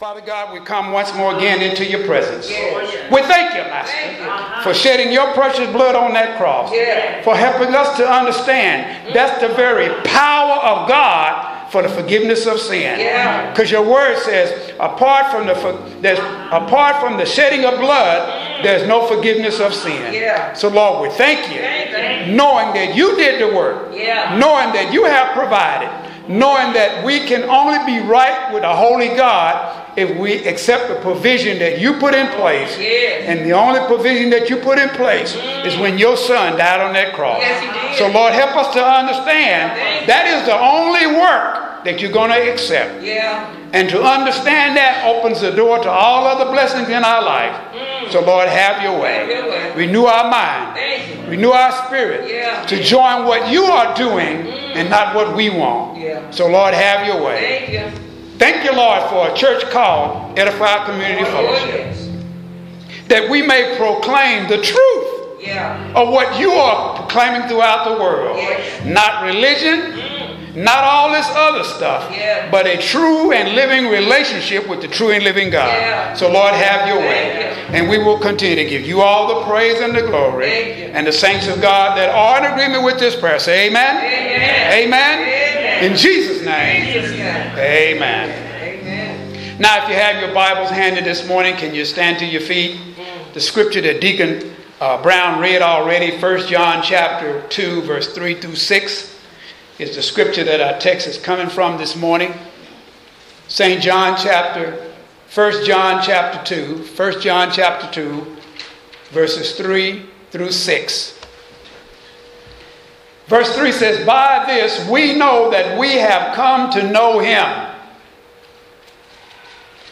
Posted in Audio Sermons